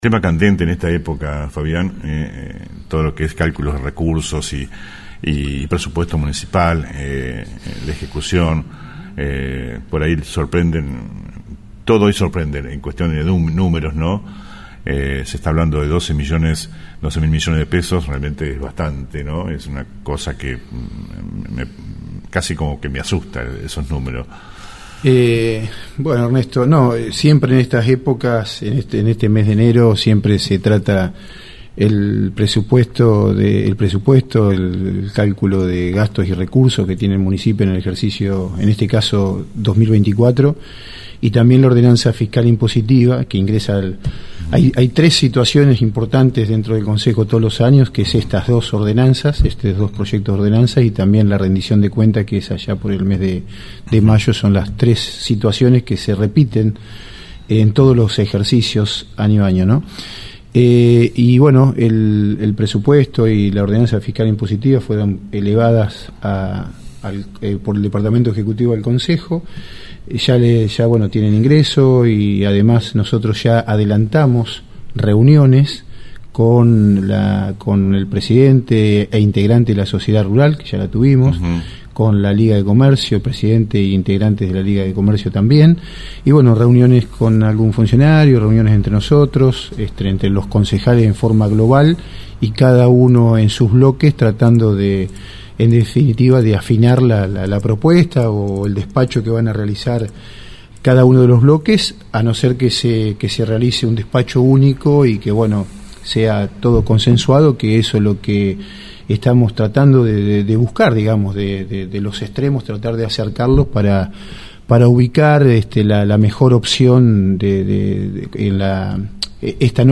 Escucha el audio completo de la entrevista a Fabian Blanstein